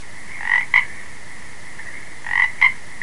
Tree Frog